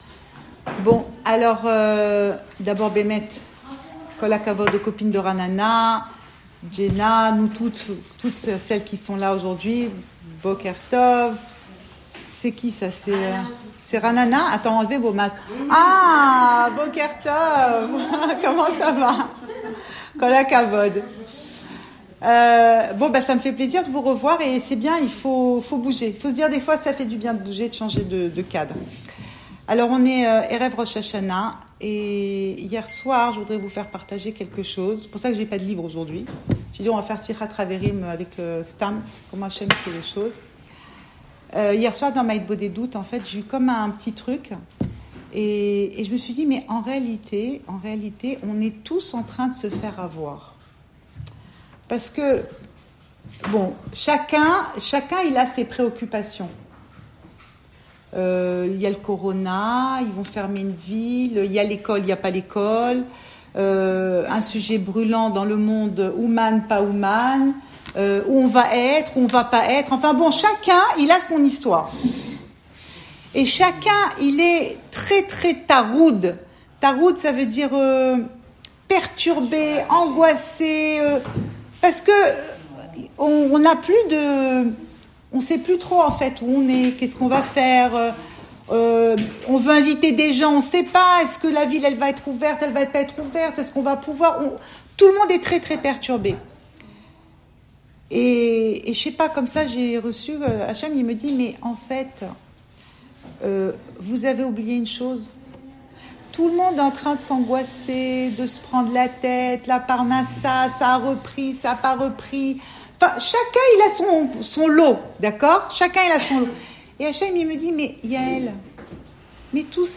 Cours audio Fêtes Le coin des femmes Pensée Breslev - 9 septembre 2020 9 septembre 2020 On se prépare à Roch Hachana ! Enregistré à Tel Aviv